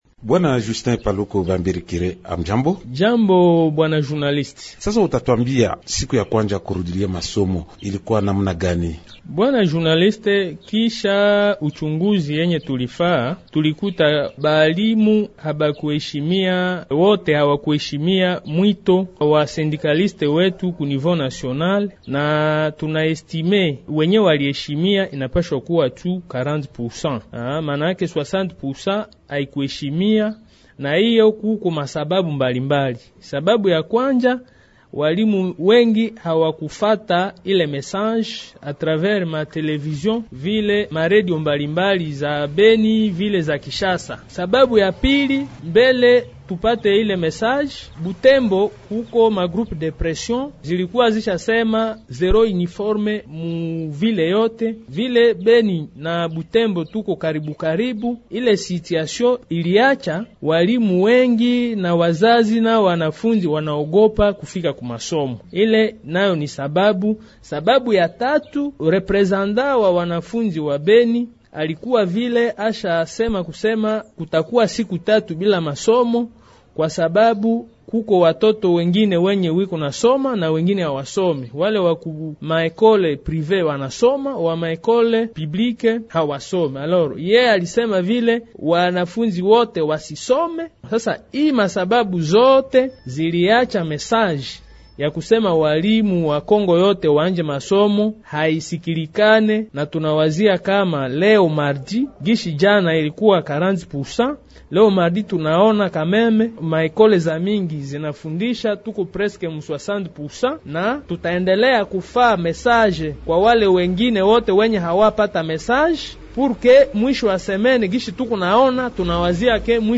L'invité swahili, Émissions / Institut Supérieur de Management, ISM, étudiants